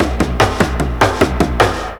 TOM FILL 2-R.wav